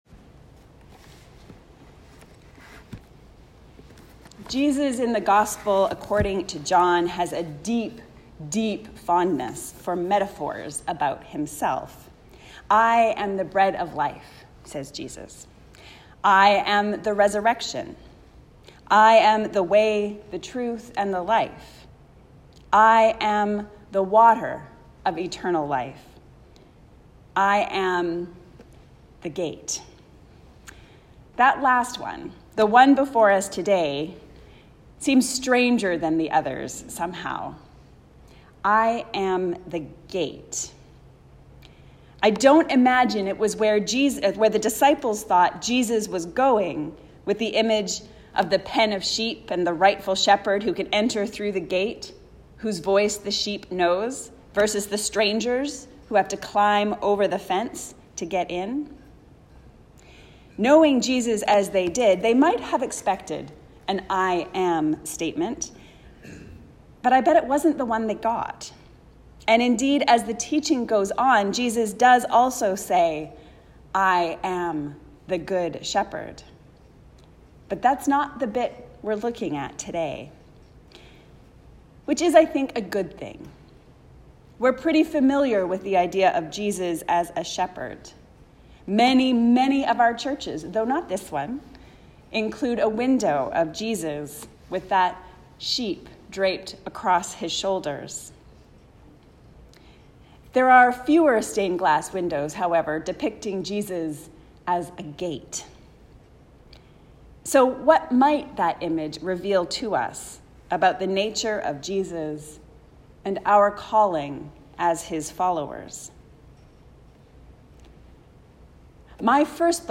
Jesus the Gate. A sermon on John 10:1-10